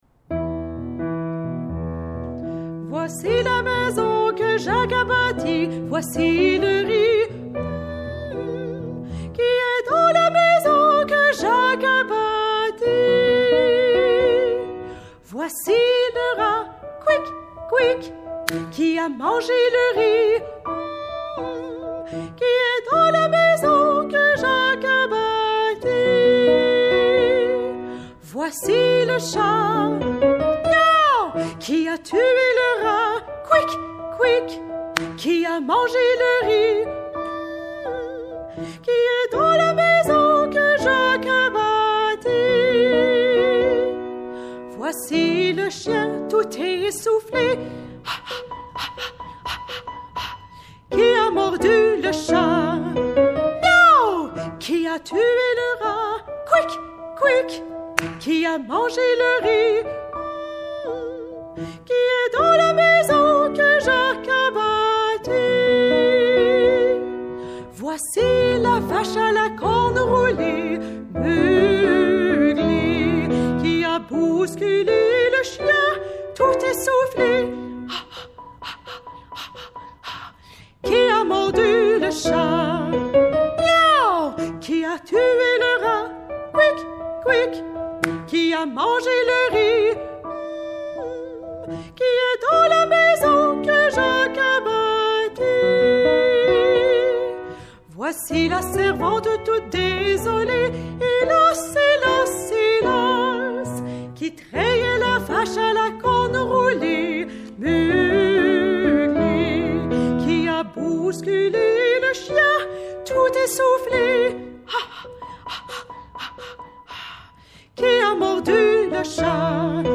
Audio version chantée